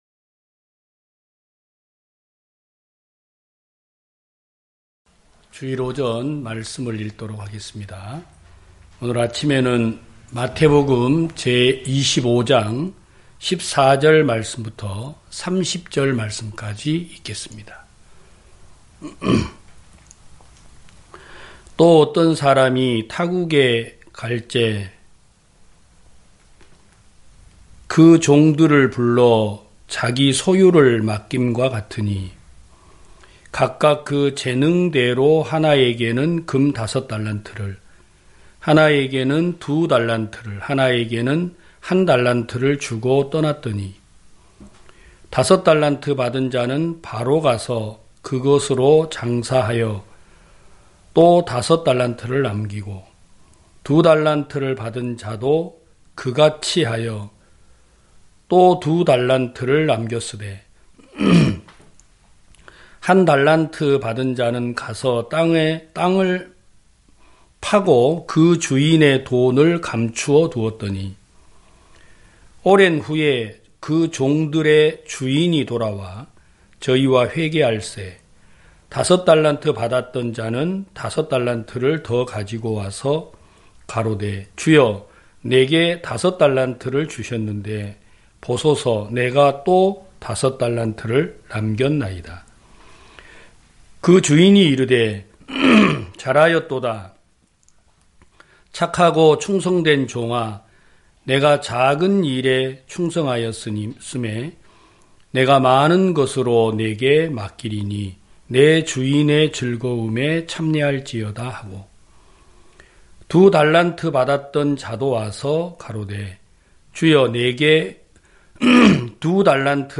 2022년 01월 09일 기쁜소식부산대연교회 주일오전예배
성도들이 모두 교회에 모여 말씀을 듣는 주일 예배의 설교는, 한 주간 우리 마음을 채웠던 생각을 내려두고 하나님의 말씀으로 가득 채우는 시간입니다.